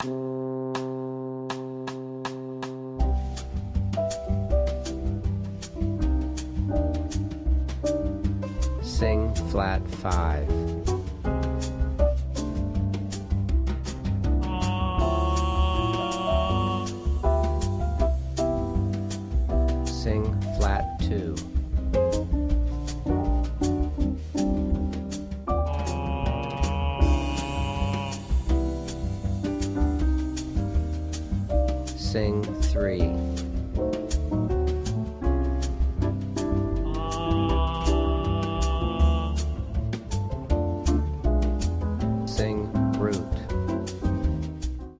• Singing Exercise with Tenor Voice